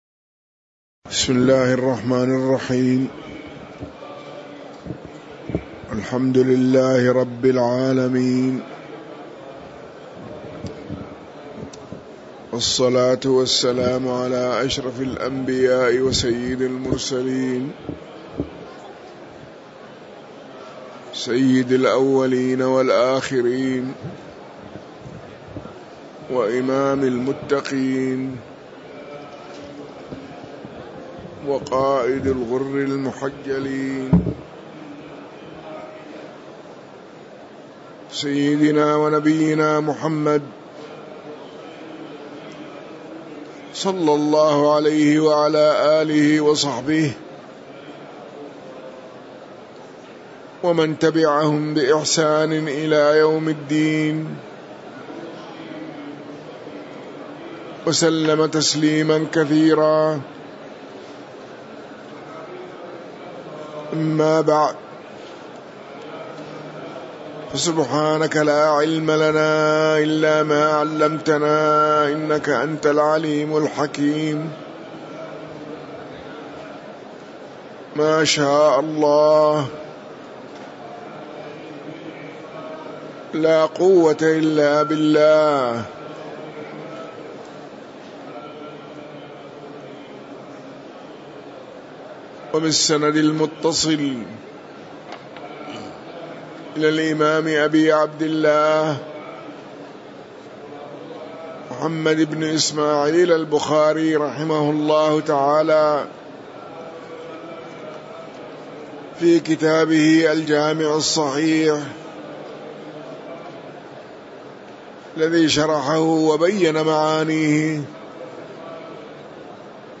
تاريخ النشر ٢٥ شوال ١٤٤٤ هـ المكان: المسجد النبوي الشيخ